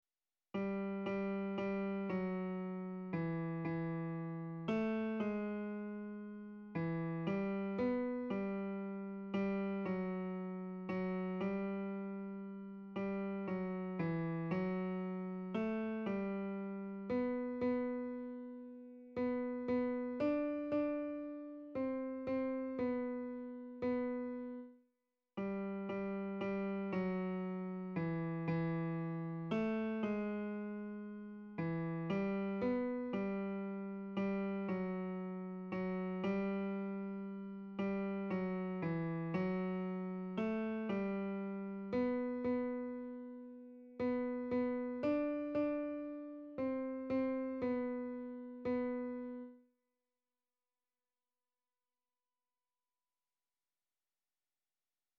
Répétition SATB par voix
Ténors
Par la musique et par nos voix_tenor copie.mp3